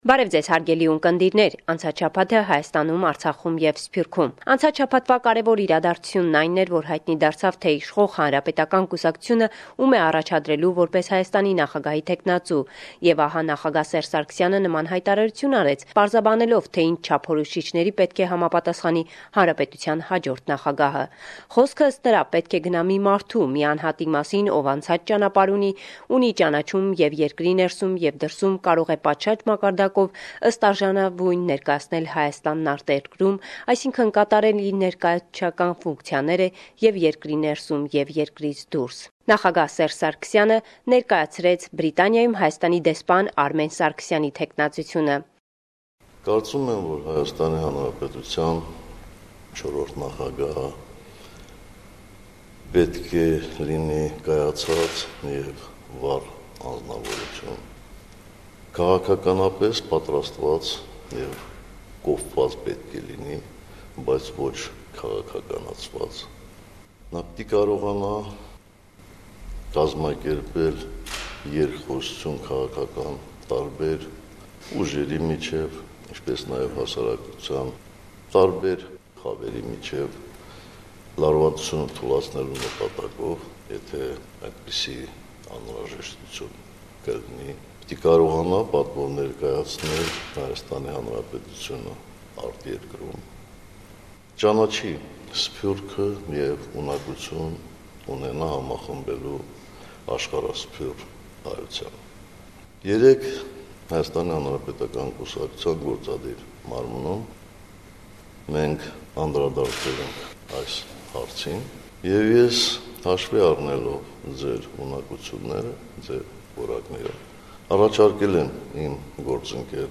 Վերջին Լուրերը – 23 Յունուար, 2018